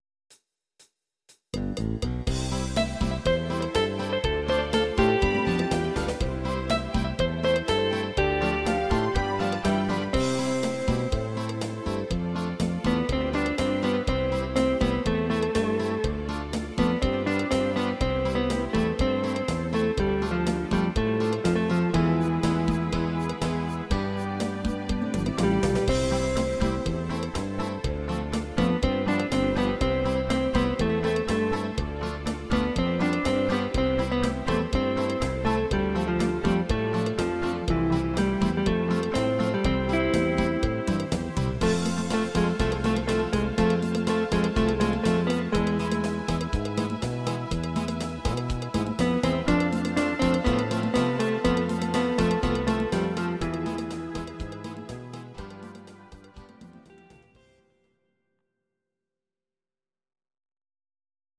These are MP3 versions of our MIDI file catalogue.
instr. Gitarre